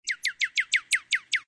SZ_TC_bird1.ogg